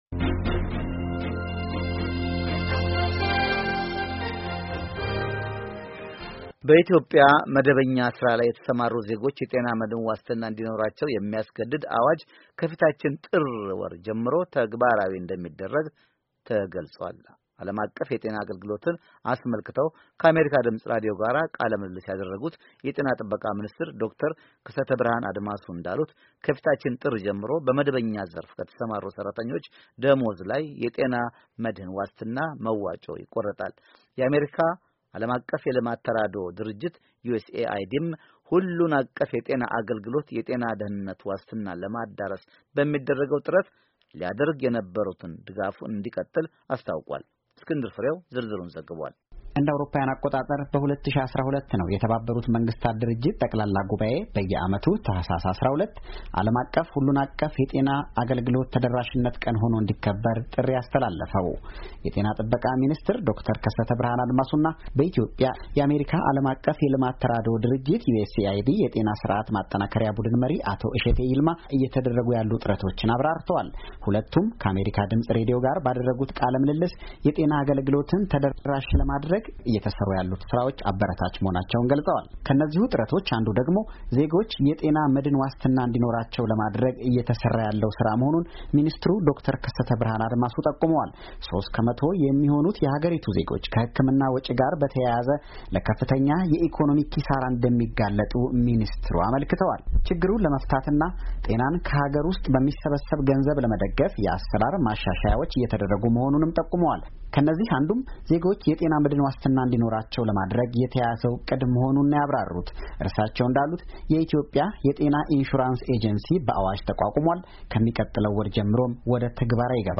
ዓለም አቀፍ የጤና አገልግሎትን አስመልከተዉ ከአሜሪካ ድምጽ ራዲዮ ጋር ቃለ ምልልስ ያደረጉት የኢትዮጵያ ጤና ጥበቃ ሚኒስትር ዶክተር ከሰተብርሃን አድማሱ እንዳሉት ከፊታችን ጥር ጀምሮ በመደበኛ ዘርፍ ከተሰማሩ ሰራተኞች ደሞዝ ላይ የጤና መድህን ዋስትና መዋጪ ይቆረጣል።